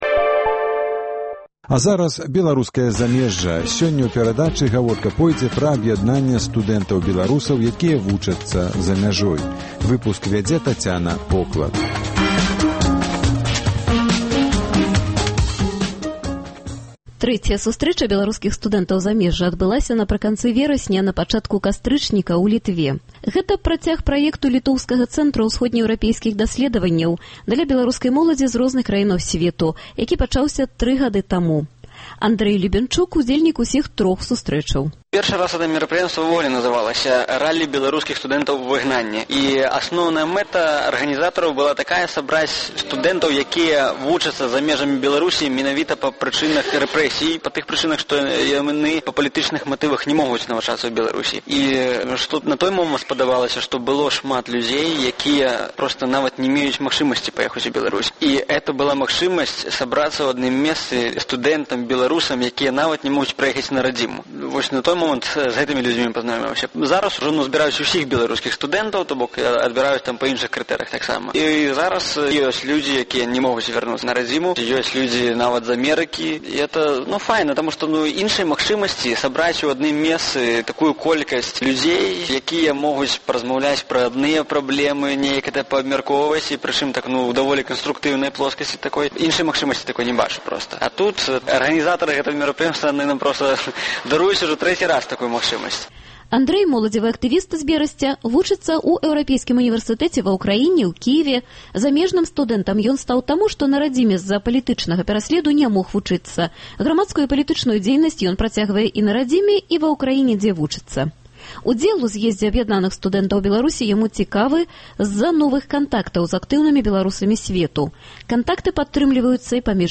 Для чаго студэнты зьбіраюцца на гэтыя сустрэчы і чым адметны сёлетні з'езд - у новай перадачы Беларускае замежжа распавядаюць студэнты з Эўропы і Амэрыкі.